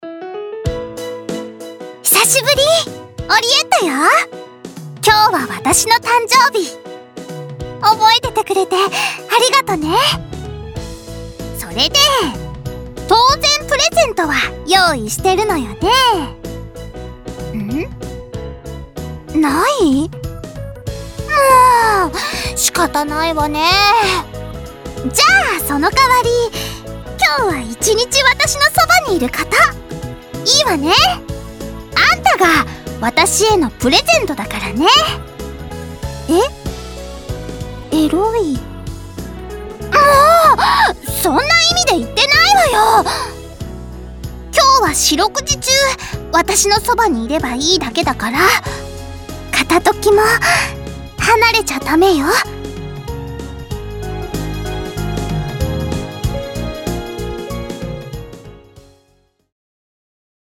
オリエッタ誕生日記念ボイスを公開しました！